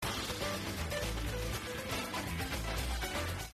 昨日録音したラジオですが、受信状態悪くてザラザーラ。
そこでノイズ野郎をあぶり出します。
♪センターキャンセルされたもの